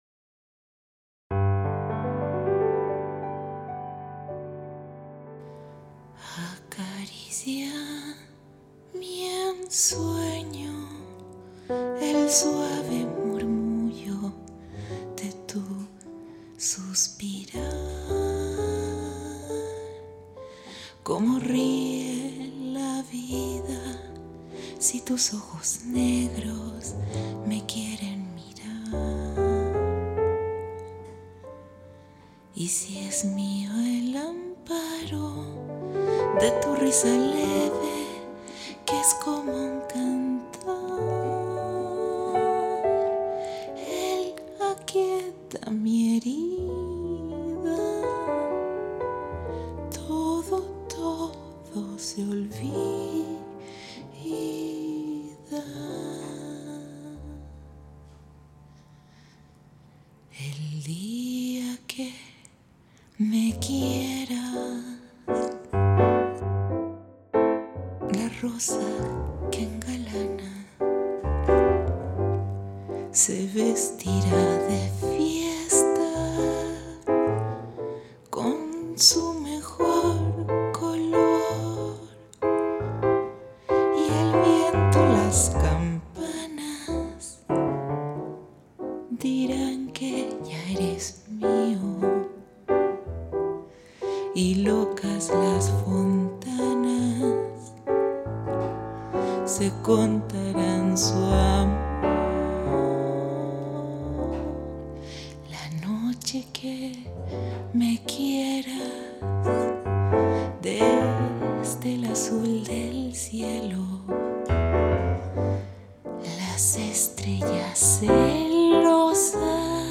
"El dia que me quieras" (Tango)
Chanteuse